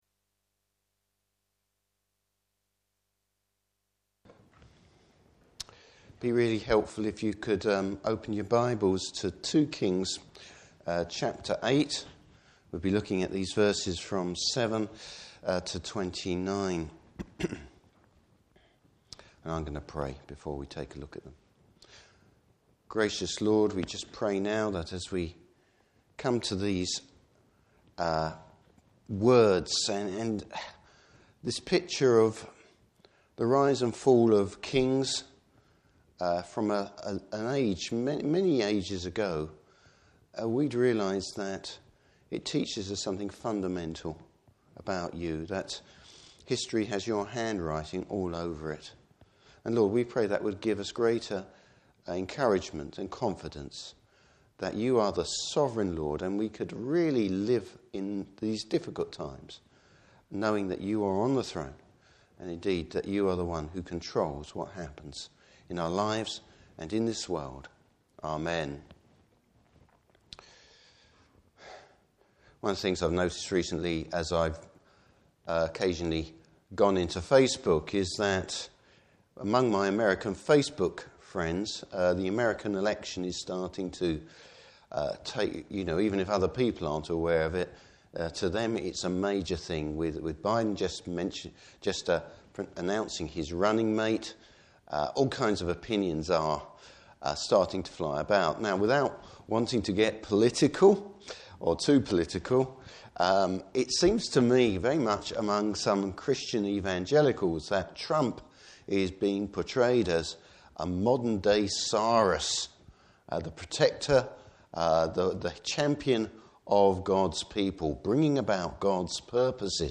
Service Type: Evening Service The world might seem in Trunoil, but it’s the Lord who pulls the strings!